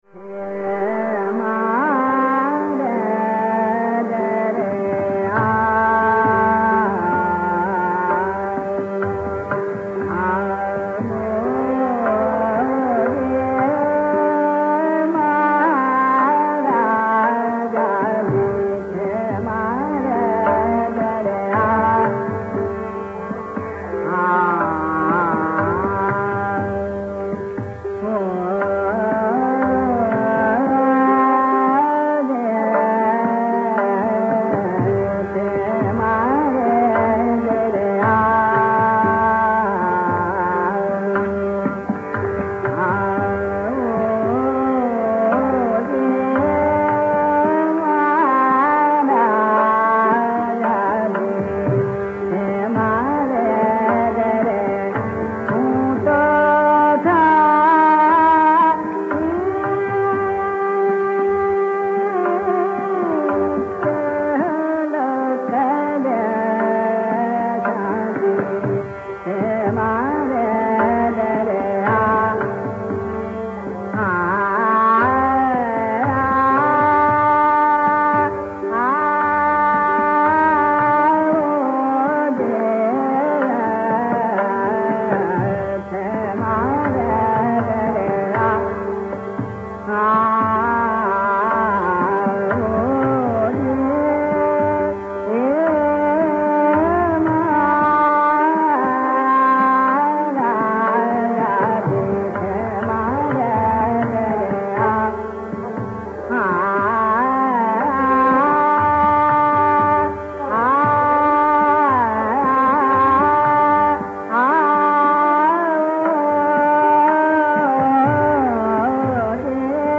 In the concluding leg, we turn to the d-flavoured Desi where, as a consequence, the Asavari anga is more explicit.
Kesarbai Kerkar‘s rendition of Sadarang’s composition, mhare dere aa’oji, reveals Jaunpuri-like M P d n S” clusters, but look out for the M P D n S” passage beginning at 2:01.